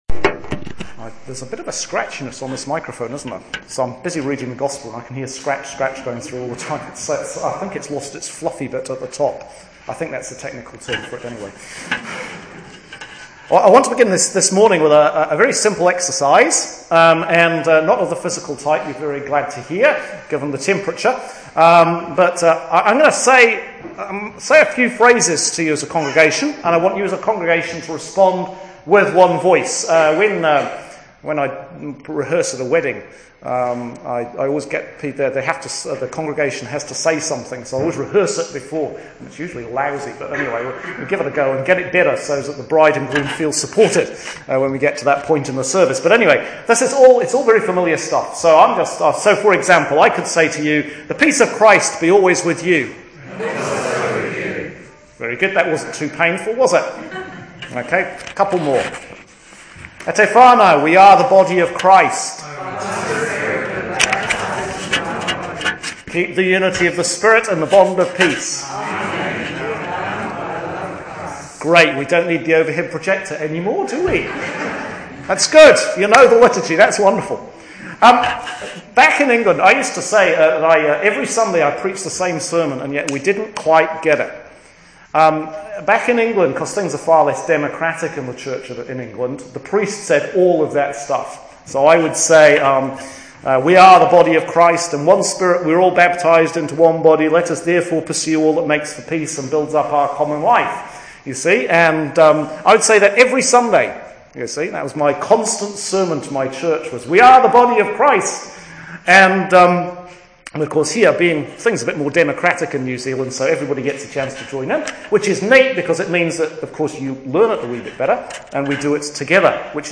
EPIPHANY 3 [click here to listen to the Sermon]